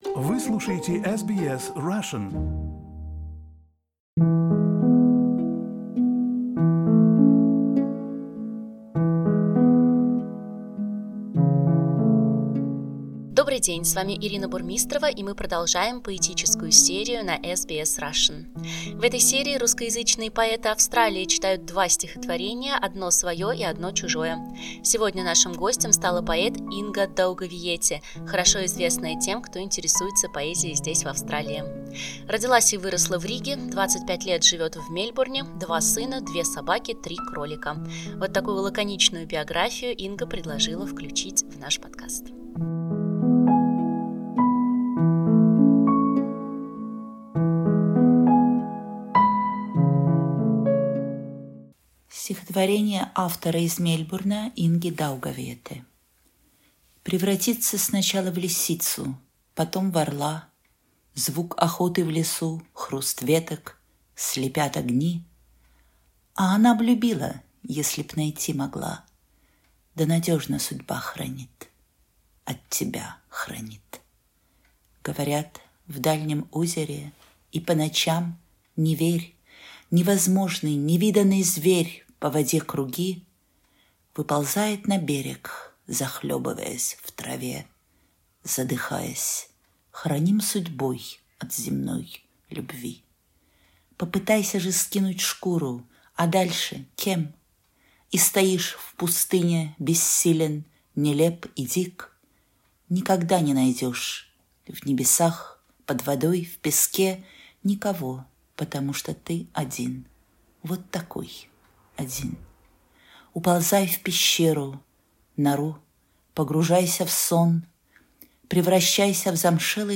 В этой рубрике мы просим австралийских поэтов прочесть два стихотворения: одно свое и одно чужое.